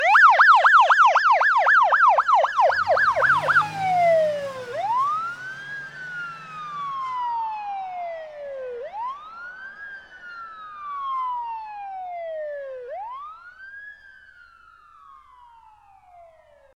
siren-ringtone_24698.mp3